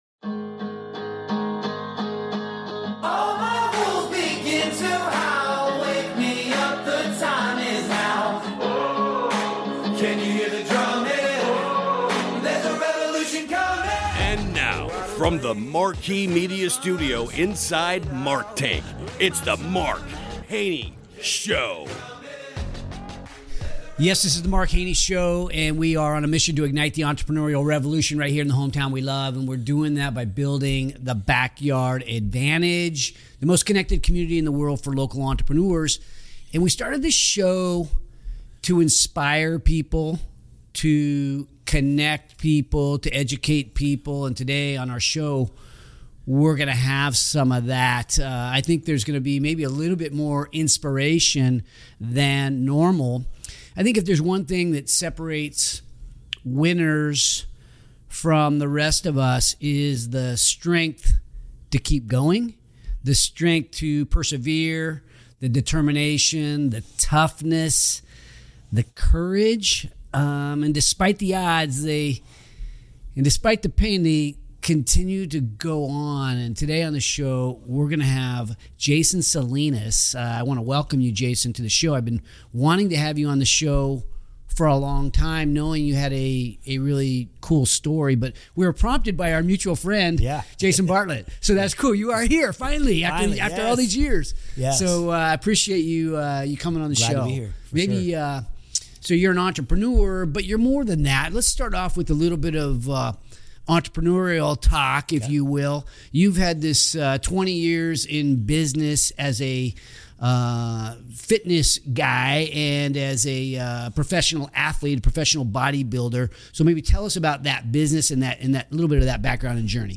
This conversation promises to motivate you to face your own journeys with renewed enthusiasm.